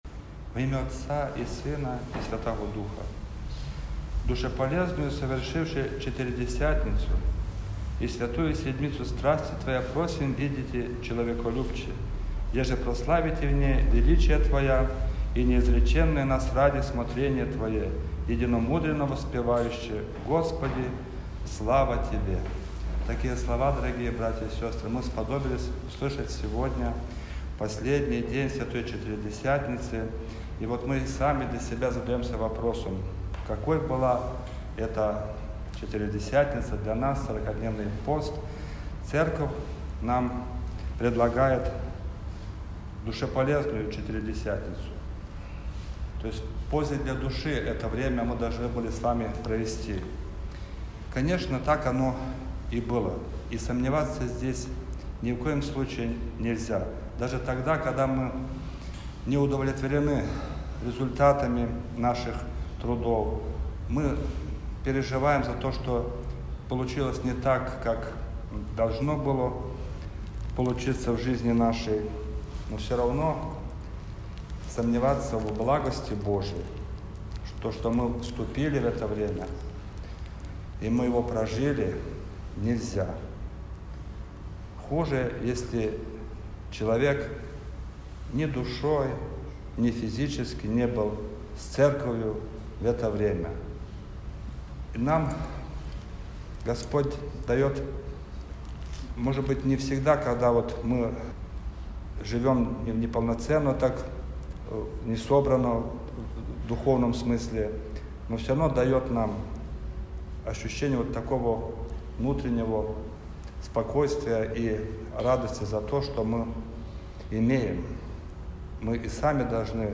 Проповедь
Литургия.mp3